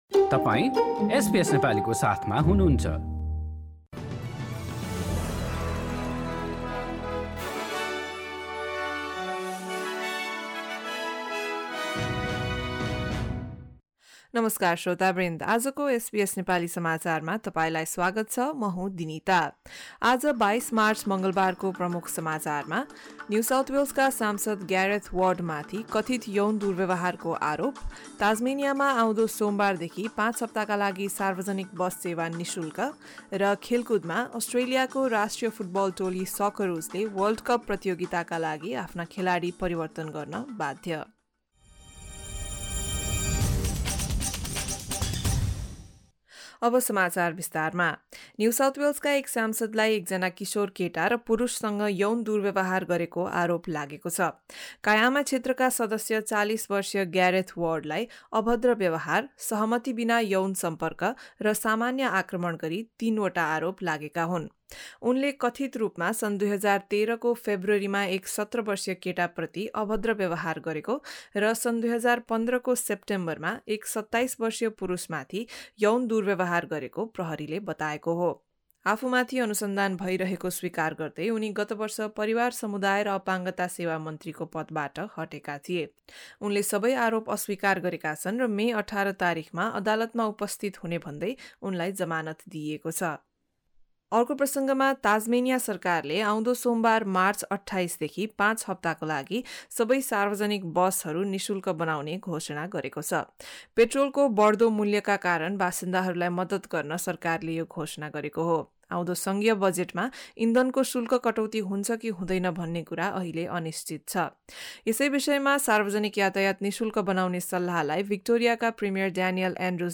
Listen to the latest news headlines from Australia in Nepali. In today's bulletin: NSW M-P Gareth Ward charged over alleged historic sexual abuse, Tasmanians to get free public bus travel for five weeks starting Monday 28 March and Socceroos again forced to make changes to their squad for two crucial World Cup qualifiers.